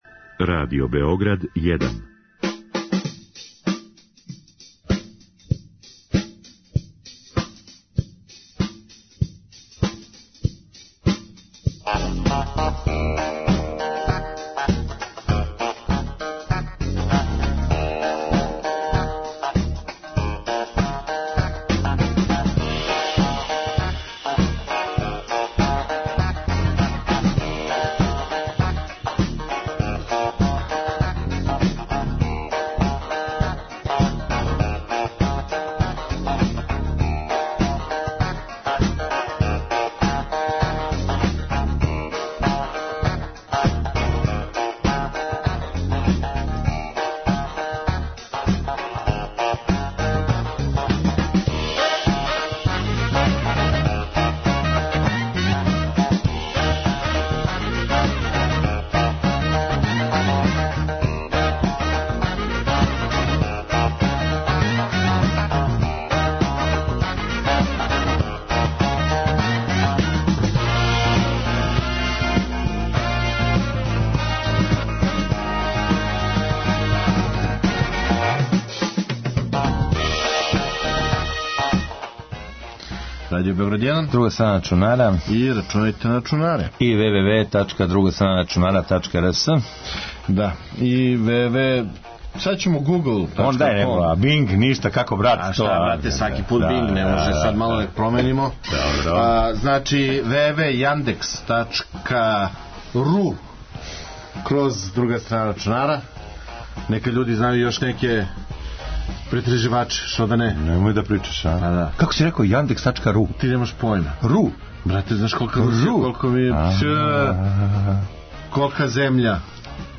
Са свима њима причаћемо одмах после њиховог наступа - неко ће бити са нама у студију, а некога ћемо чути путем телефона.